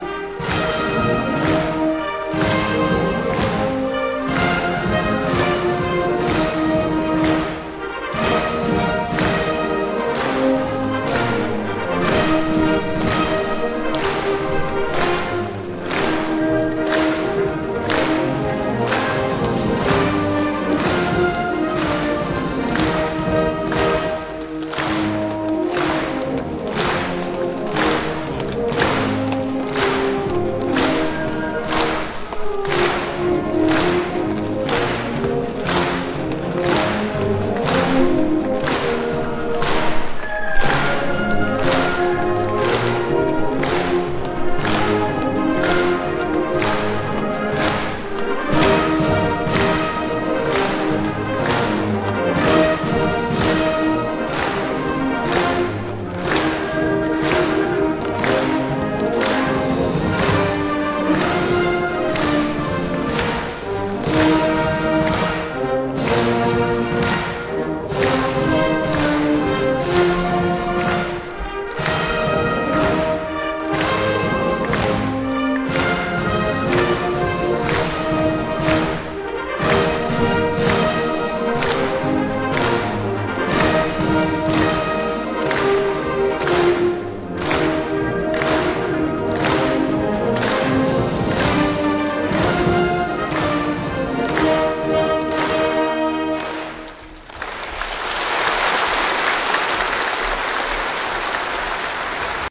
中国共产党第二十次全国代表大会16日上午在人民大会堂开幕。习近平代表第十九届中央委员会向党的二十大作报告。